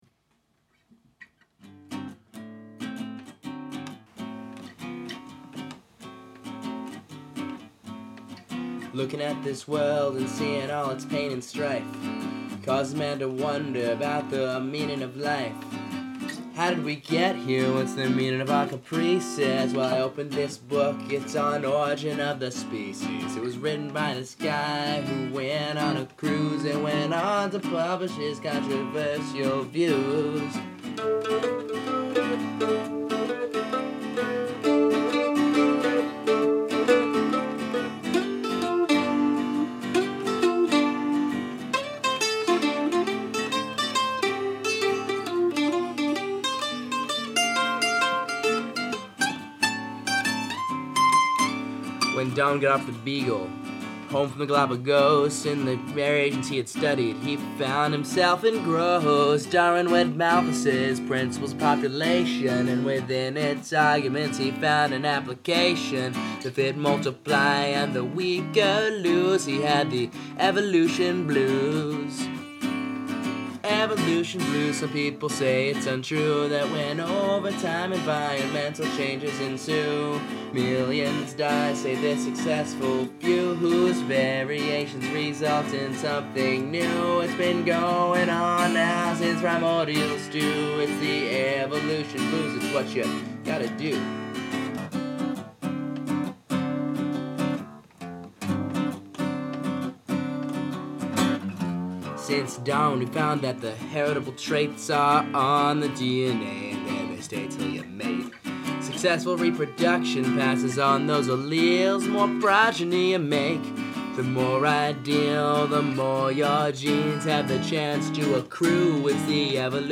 A lighthearted take on the issues:
Evolution-Blues.mp3